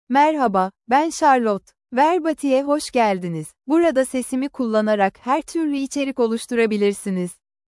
CharlotteFemale Turkish AI voice
Charlotte is a female AI voice for Turkish (Turkey).
Voice sample
Listen to Charlotte's female Turkish voice.
Charlotte delivers clear pronunciation with authentic Turkey Turkish intonation, making your content sound professionally produced.